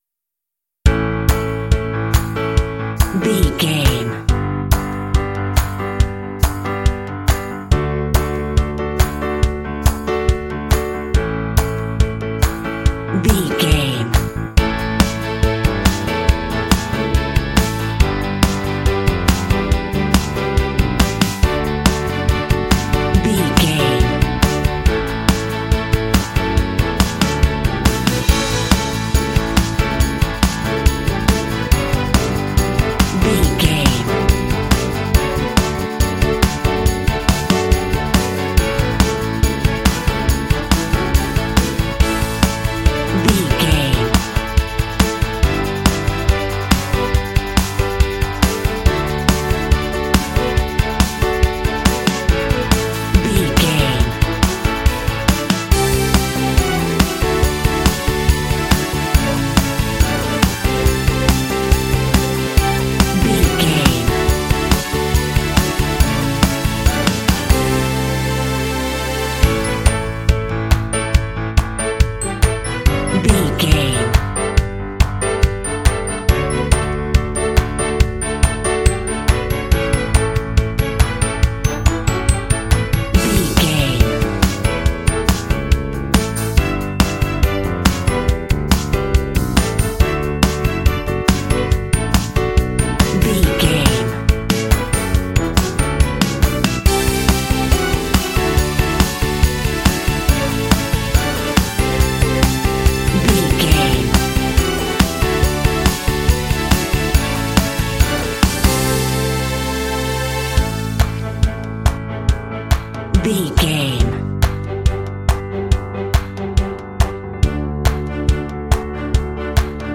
Uplifting
Mixolydian
optimistic
happy
bright
piano
drums
strings
electric guitar
bass guitar
synthesiser
rock
contemporary underscore
indie